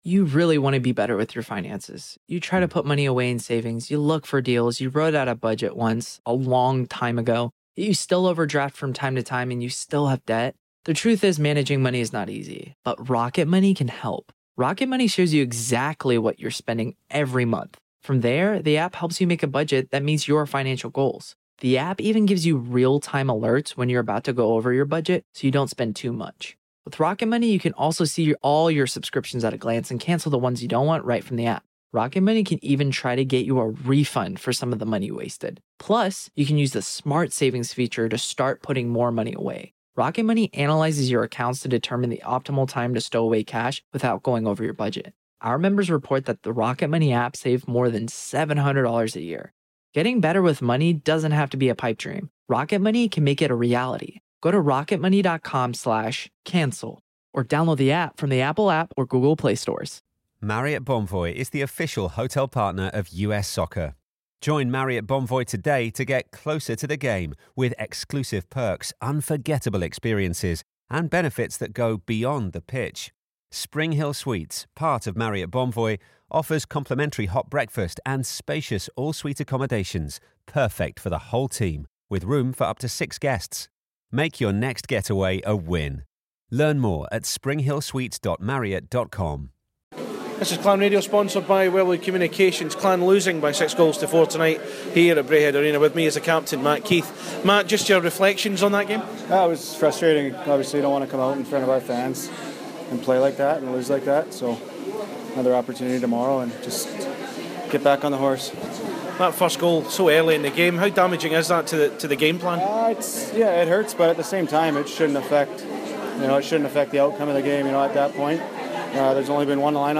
talks to Clan Radio post match as Dundee Stars take the points in a 6-4 win for them